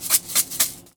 R - Foley 47.wav